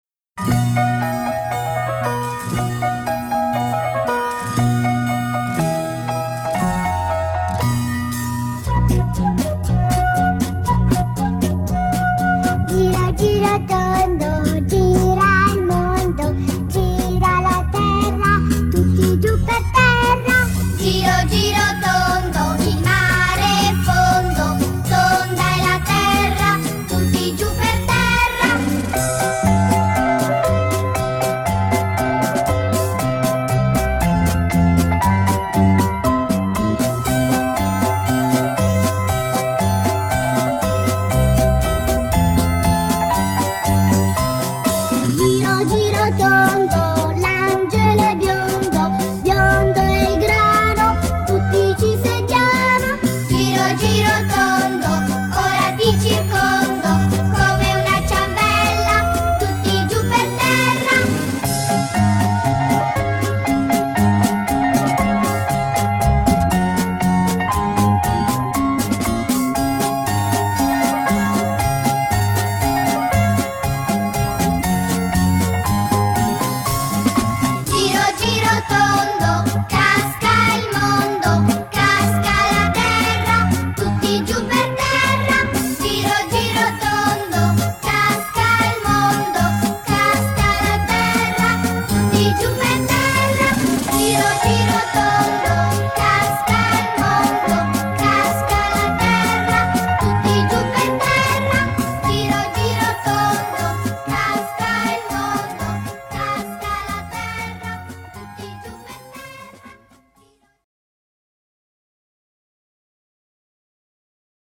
CANZONCINA: Giro giro tondo